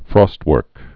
(frôstwûrk, frŏst-)